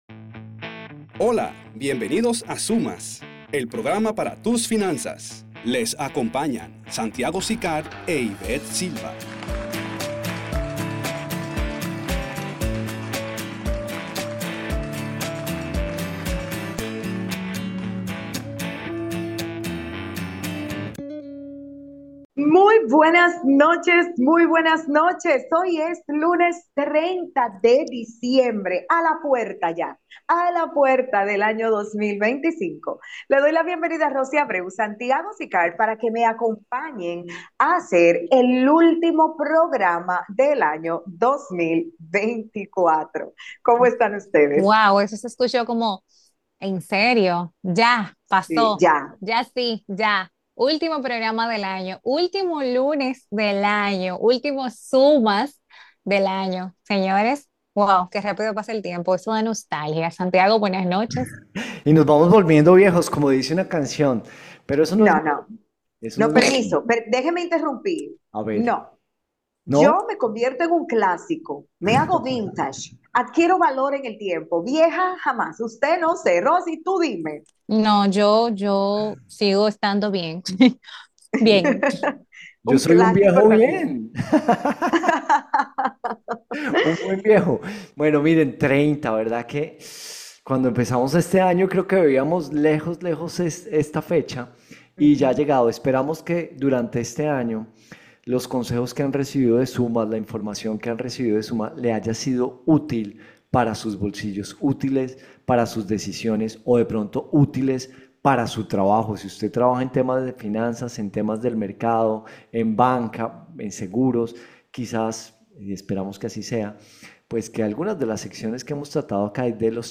Escucha todo nuestro programa de radio de hoyy.